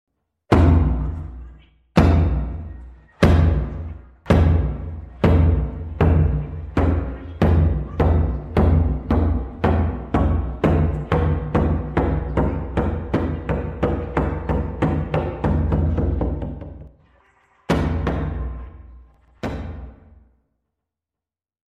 Tiếng Trống vào lớp, tiếng Trống trường
Thể loại: Tiếng đồ vật
Description: Đây là hiệu ứng âm thanh tiếng trống vào lớp, tiếng trống trường đánh thông báo đã đến giờ vào học mp3 chất lượng cao.
tieng-trong-vao-lop-www_tiengdong_com.mp3